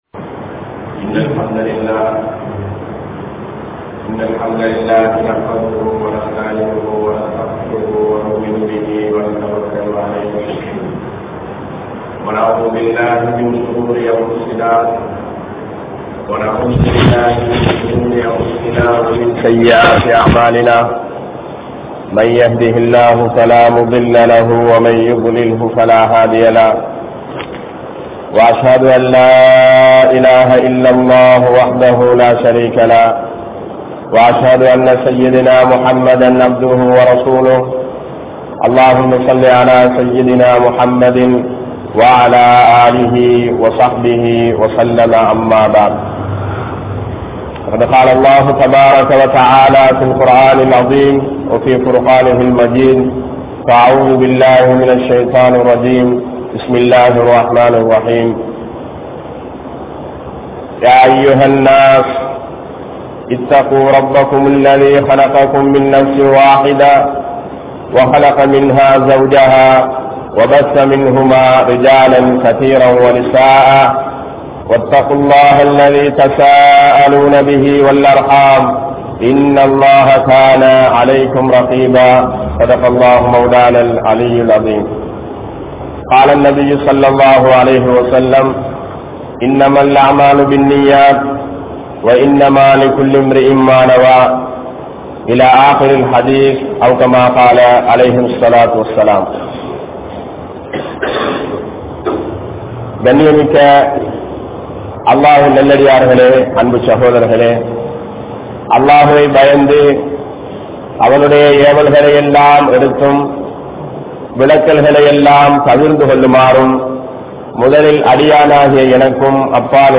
Islam Koorum Suththam(Part02) (இஸ்லாம் கூறும் சுத்தம்) | Audio Bayans | All Ceylon Muslim Youth Community | Addalaichenai
Gallella Jumua Masjidh